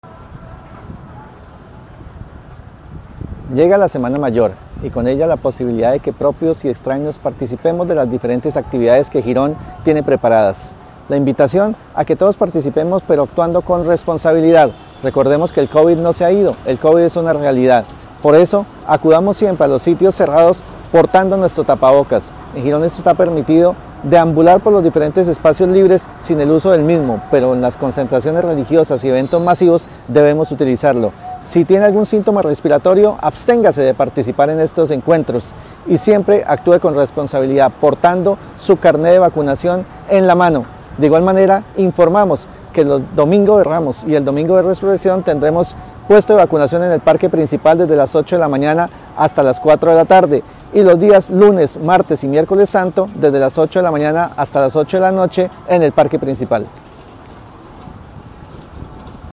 John Forero - Secretario de Salud Municipal.mp3